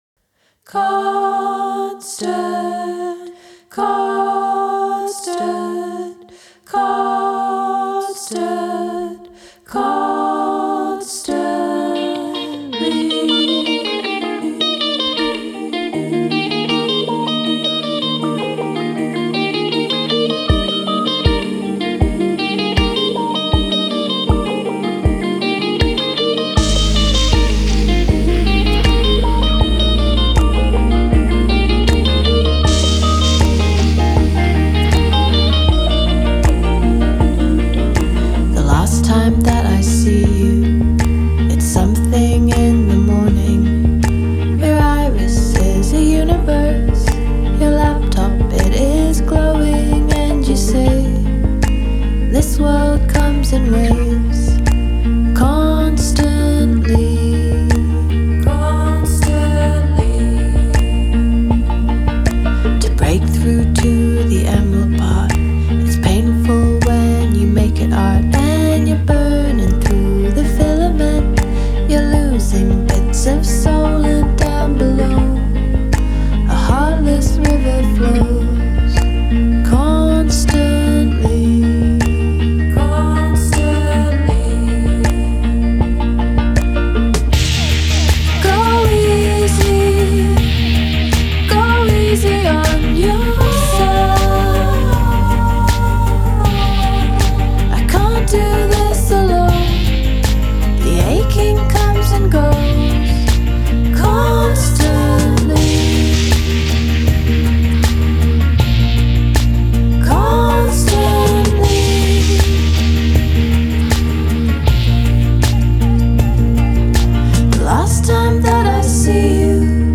Genre: Indie Folk, Singer-Songwriter